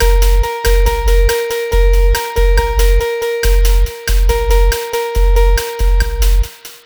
Cheese Lik 140-A#.wav